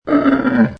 Camel 13 Sound Effect Free Download